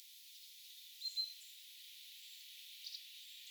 tuollaista ääntä piti ilmeisesti
yksi talitiainen?
tuollaista_aanta_ilm_piti_yksi_talitiainen.mp3